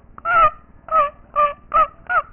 Turkey Call I.D.
turkey.wav